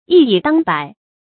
一以当百 yī yǐ dāng bǎi 成语解释 一人抵过百人。